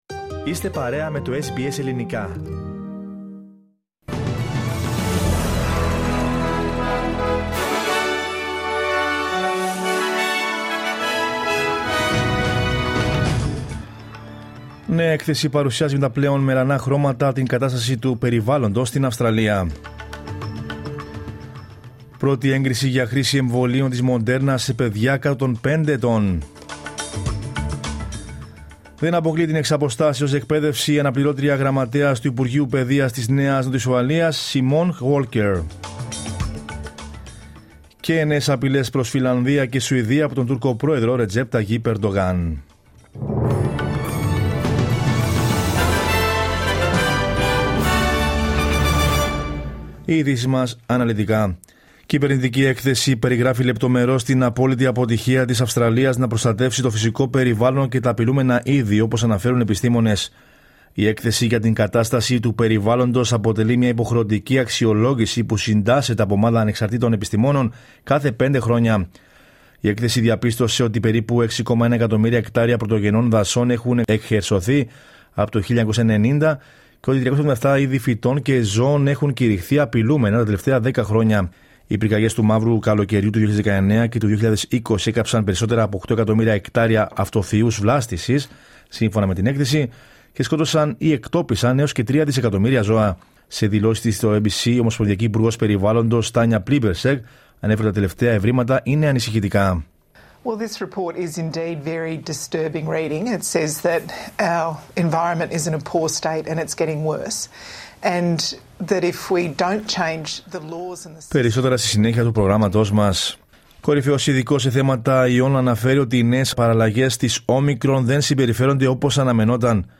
News in Greek from Australia, Greece, Cyprus and the world is the news bulletin of Tuesday 19 July 2022.